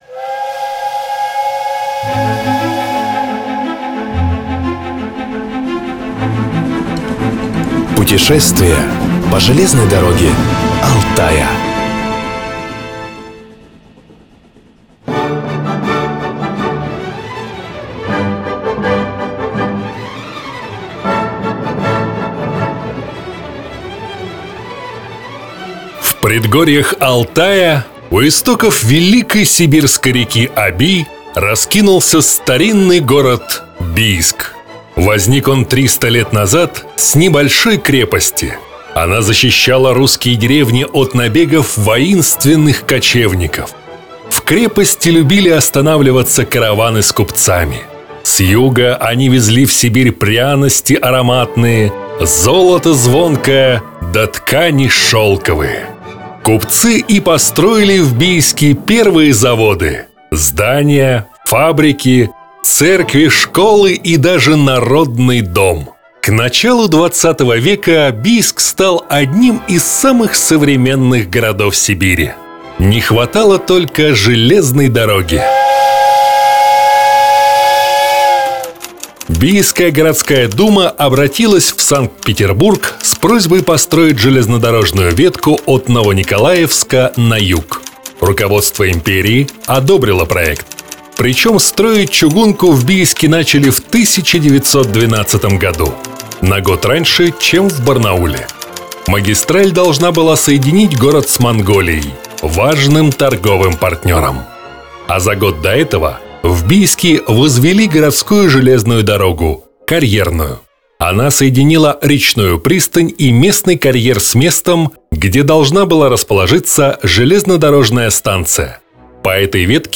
Это наш новый – как всегда необычный и интересный – аудиогид.